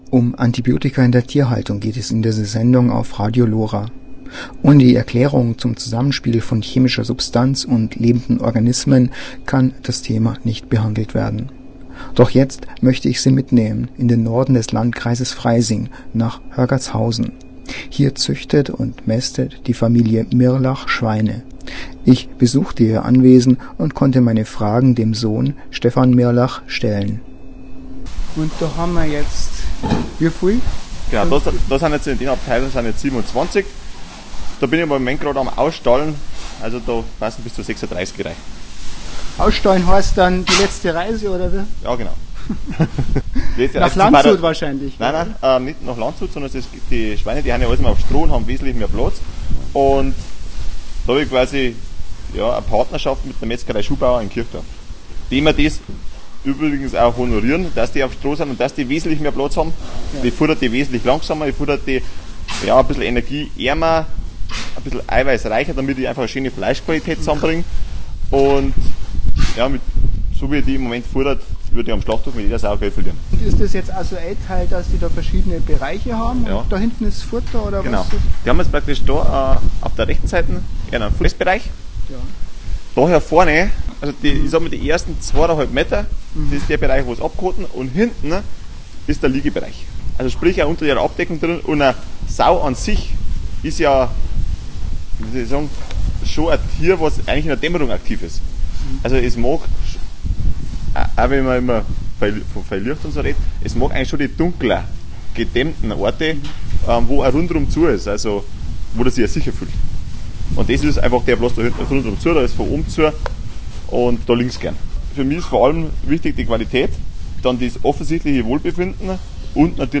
Tierhaltung auf einem kleinen oberbayerischen Hof